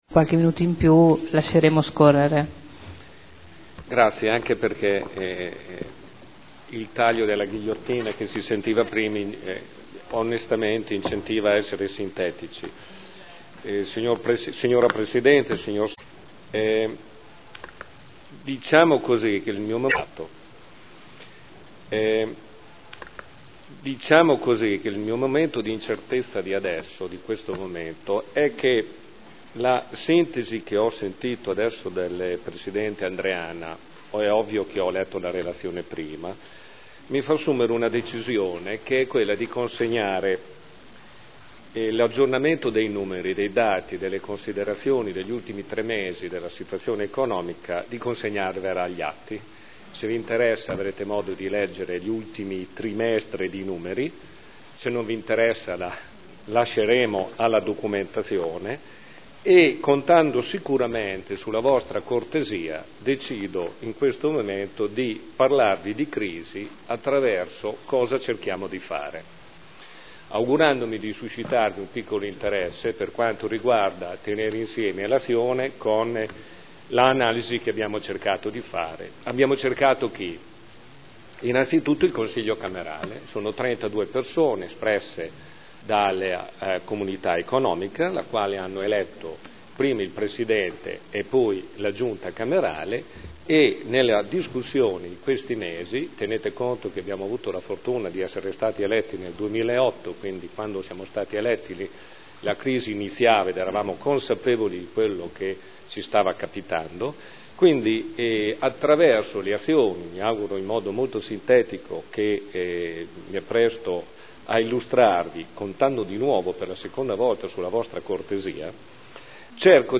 Maurizio Torreggiani — Sito Audio Consiglio Comunale